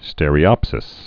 (stĕrē-ŏpsĭs, stîr-)